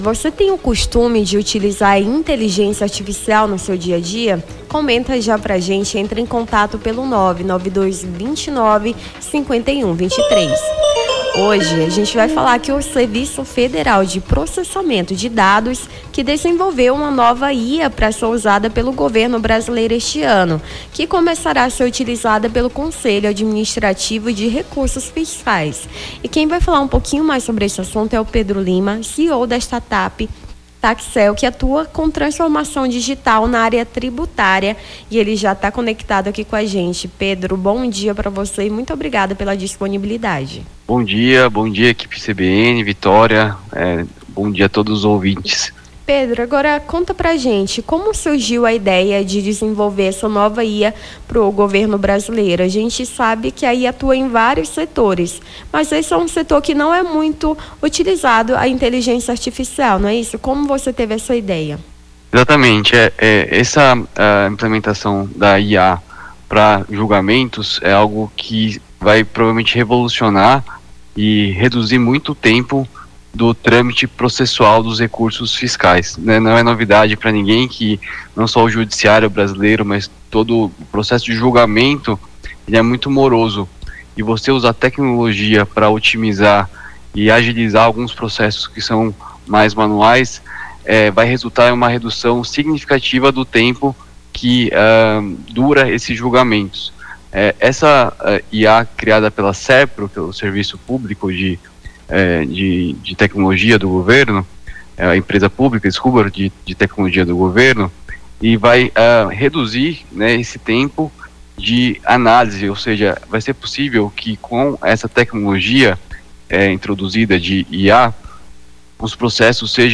Nome do Artista - CENSURA - ENTREVISTA IA FACILITA PROCESSOS (17-02-25).mp3